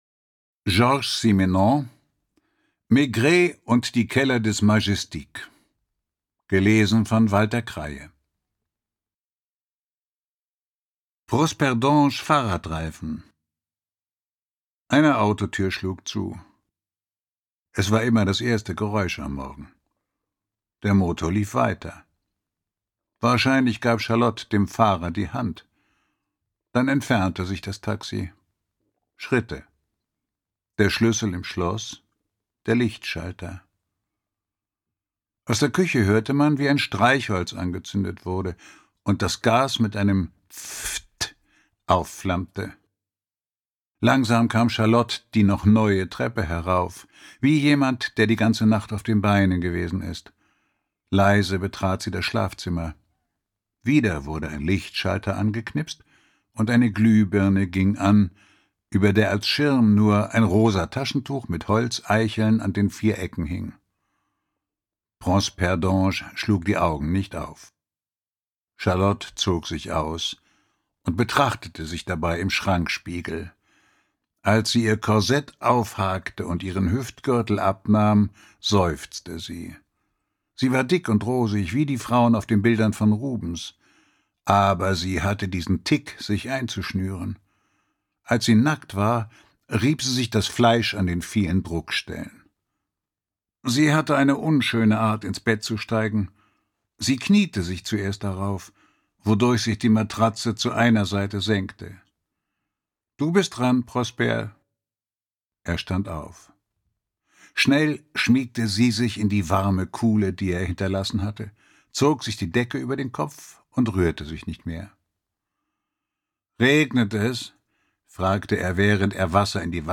20. Fall. Ungekürzte Lesung mit Walter Kreye (4 CDs)
Walter Kreye (Sprecher)